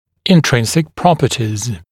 [ɪn’trɪnsɪk ‘prɔpətɪz] [-zɪk][ин’тринсик ‘пропэтиз] [-зик]внутренние свойства